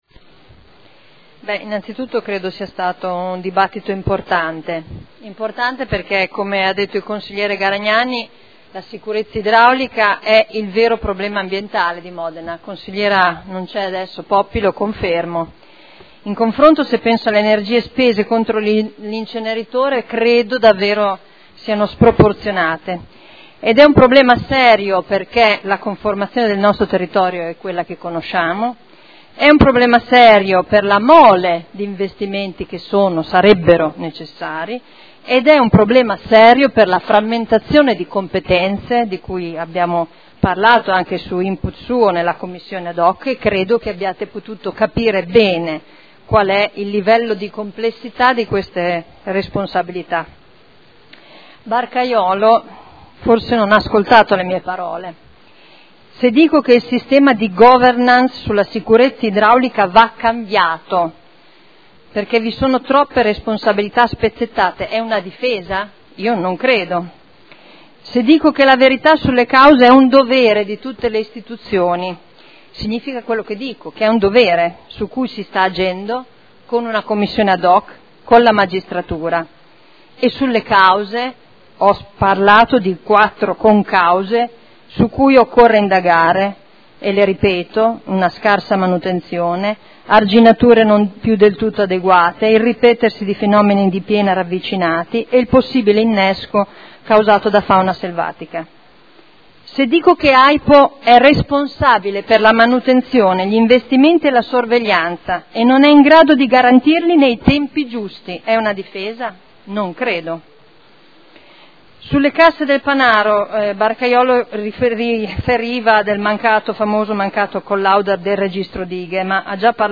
Simona Arletti — Sito Audio Consiglio Comunale
Seduta del 30/01/2014. Conclude dibattito su interrogazioni riguardanti l'esondazione del fiume Secchia.